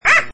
AH_BEEP.mp3